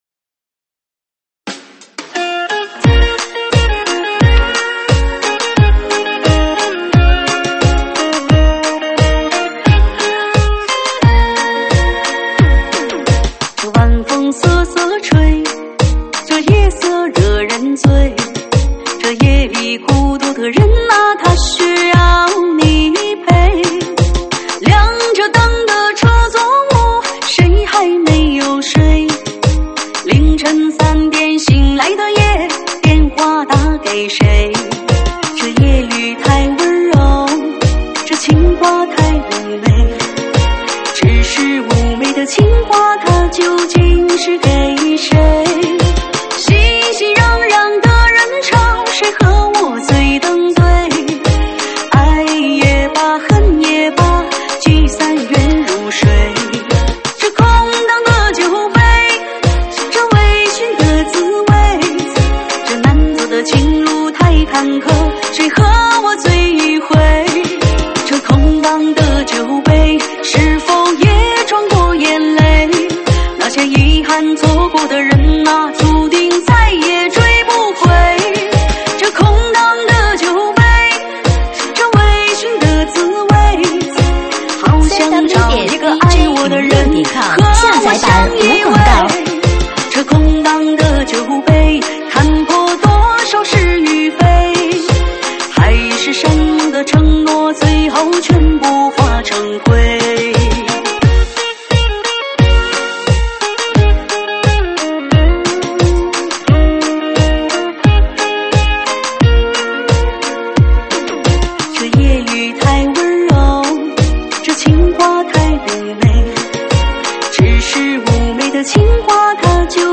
舞曲类别：水兵舞